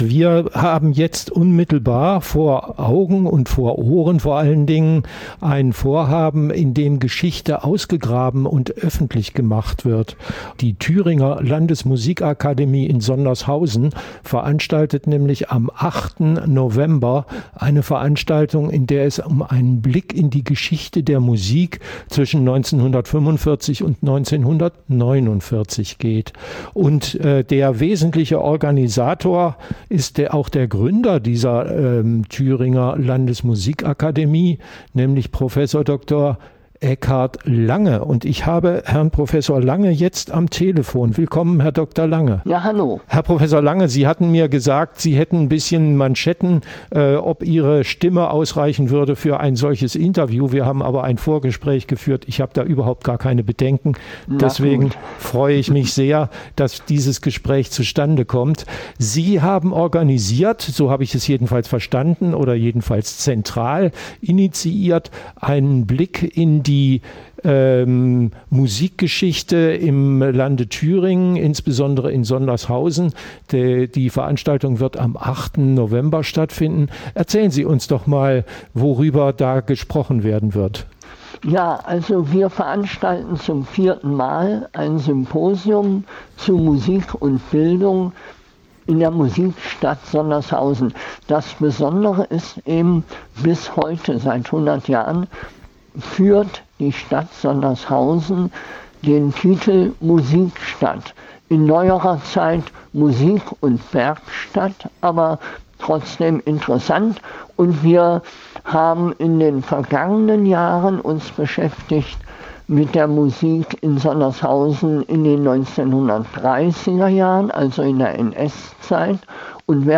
Musik in Thüringen in den Jahren 1945 bis 1949 - Ein Gespräch